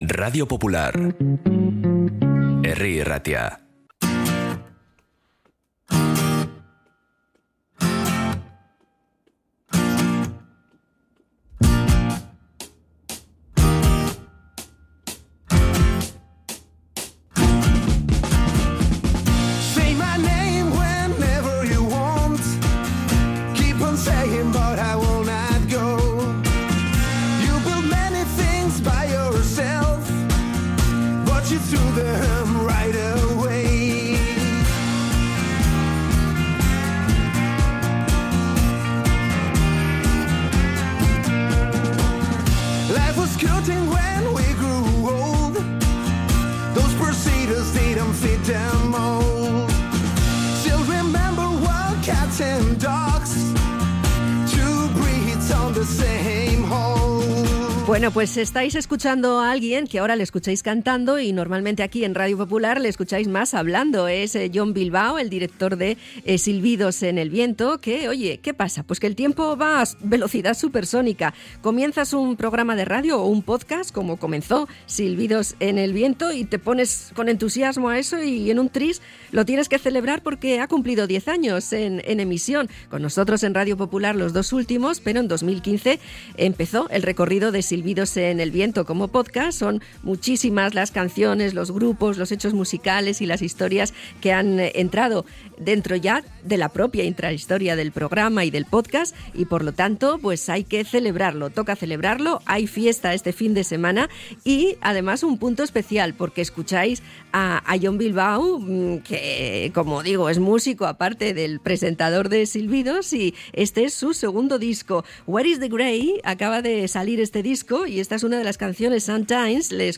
Le hemos felicitado en EgunON Magazine.